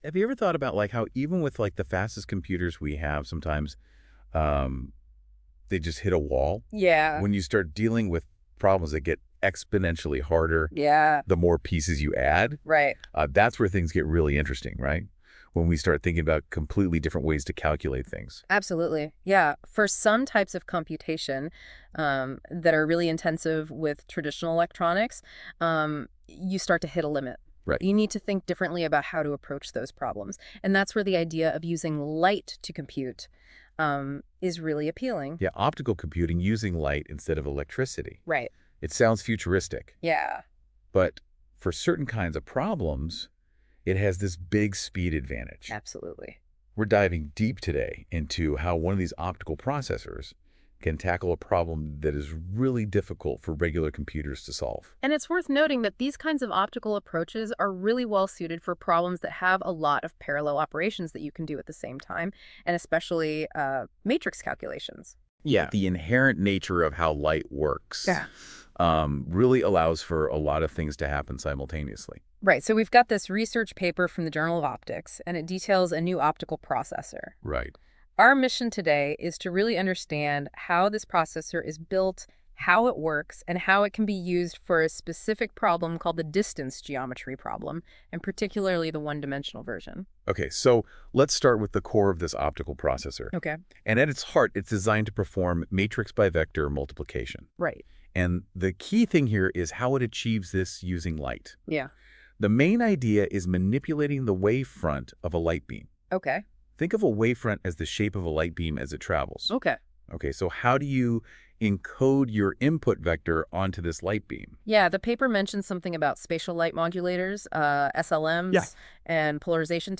Hey guys, I've just tried this new Google's NotebookLM functionality, where you provide a bunch of sources and it generates for you an audio summary of its content in the form of a podcast. This is what it was produced from one of our papers: a podcast about our optical processor !